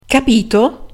And here is our mum again, this time making sure the child has understood that he’s not going to have that extra gelato: